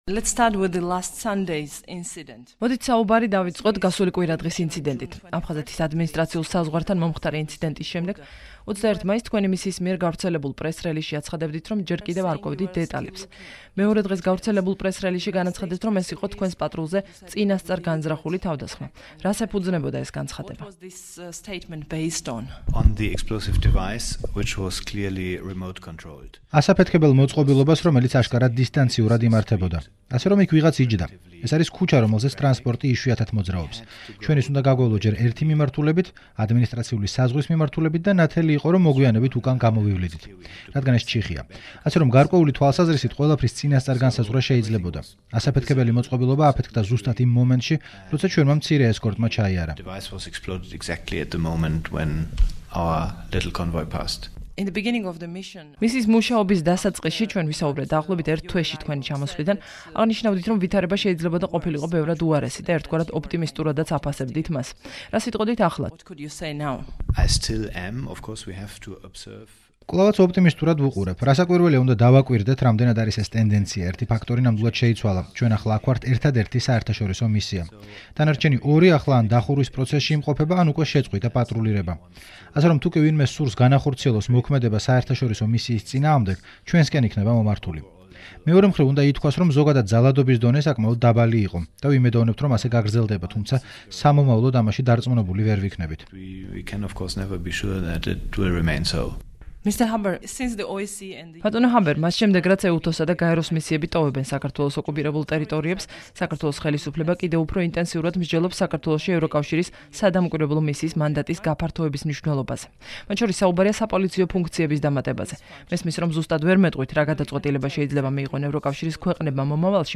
ინტერვიუ ჰანსიორგ ჰაბერთან